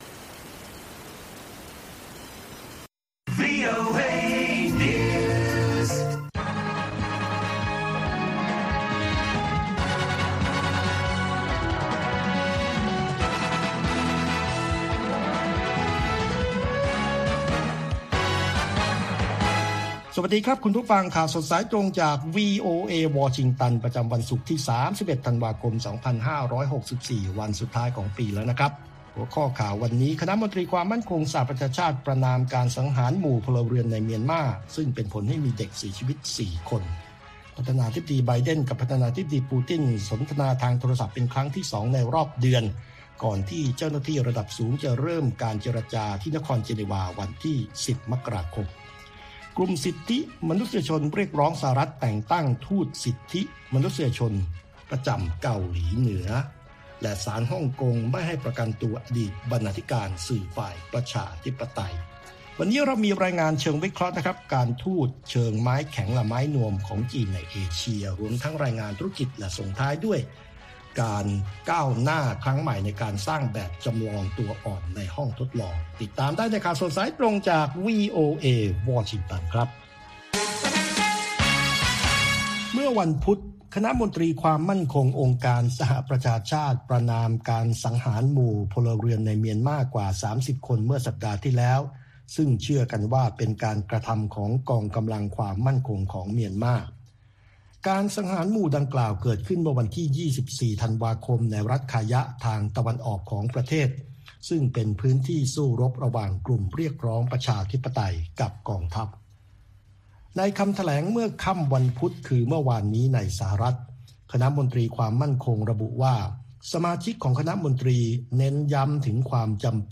ข่าวสดสายตรงจากวีโอเอ ภาคภาษาไทย 8:30–9:00 น. ประจำวันศุกร์ที่ 31 ธันวาคม ตามเวลาในประเทศไทย